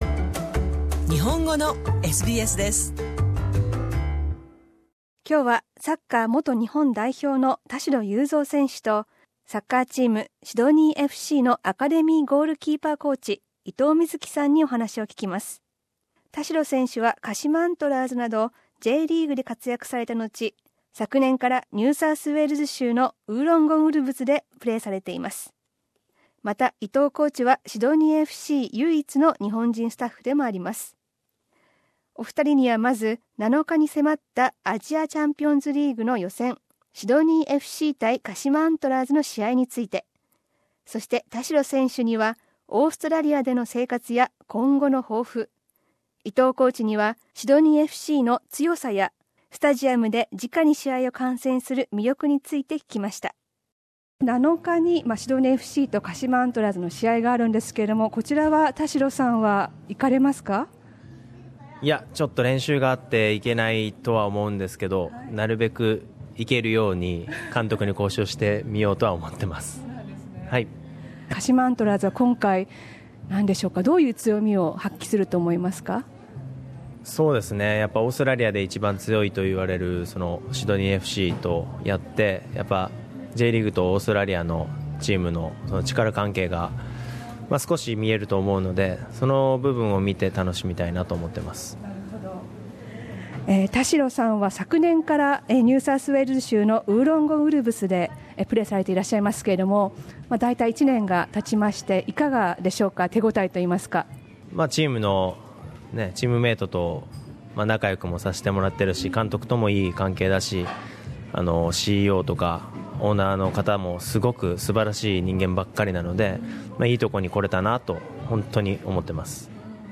7日夜にシドニーで行われるシドニーＦＣ対鹿島アントラーズの試合を前に、お話を聞きました。